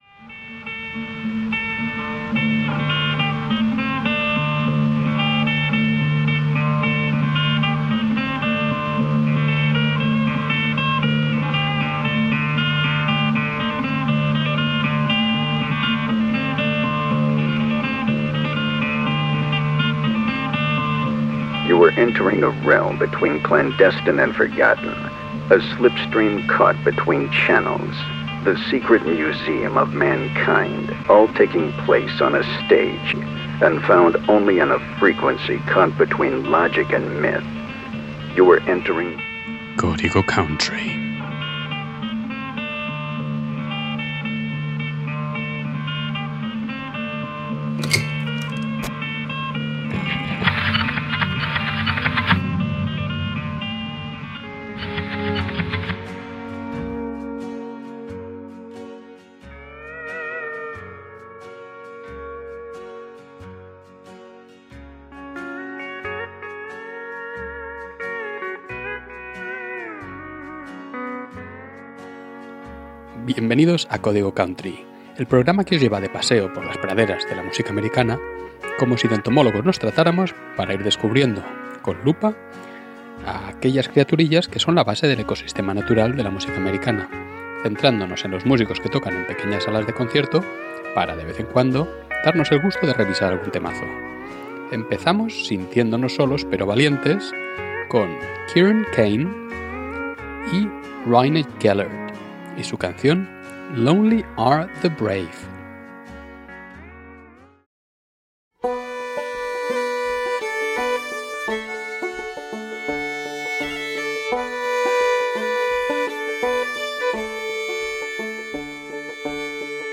En este episodio, nos aventuramos en un emocionante safari musical a través de las praderas de la música country, explorando una variedad de especies sonoras que habitan en este ecosistema musical.